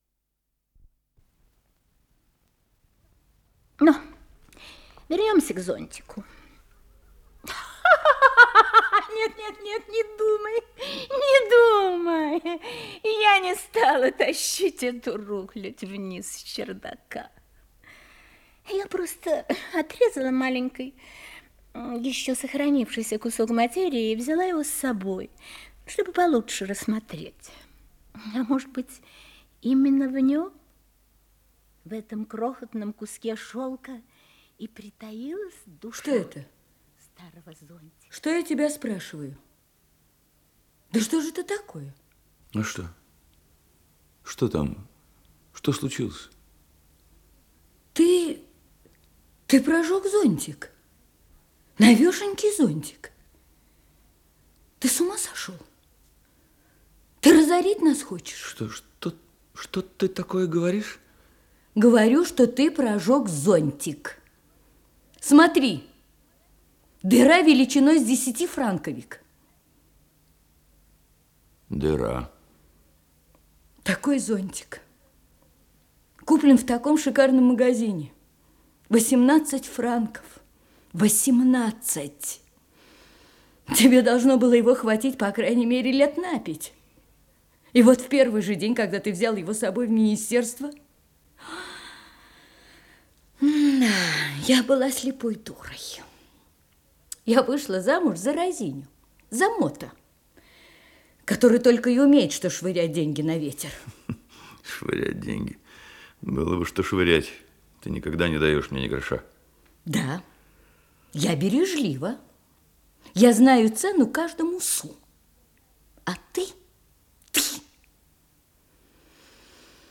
Исполнитель: Артисты московских театров
История в трёх письмах, радиопостановка